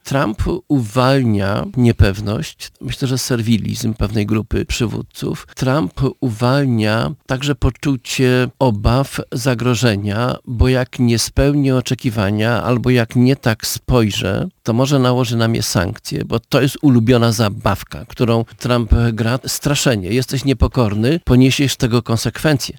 [Poranna Rozmowa]